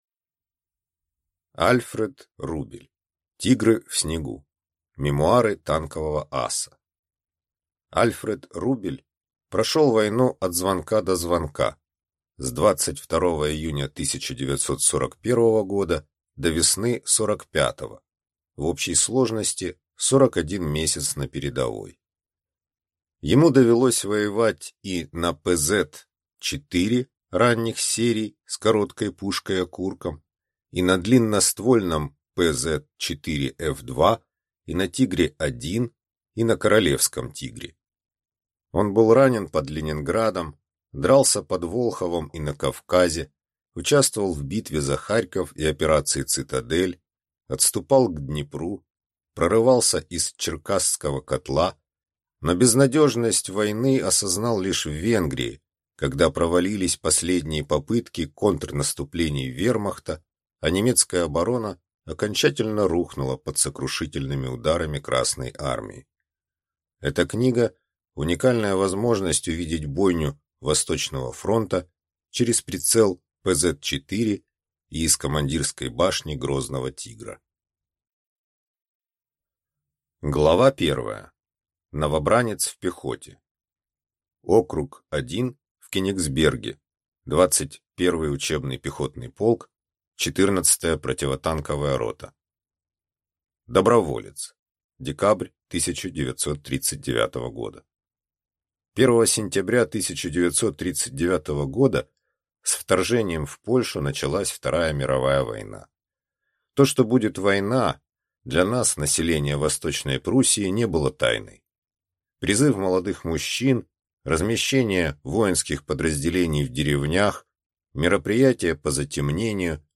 Аудиокнига «Тигры» в снегу. Мемуары танкового аса | Библиотека аудиокниг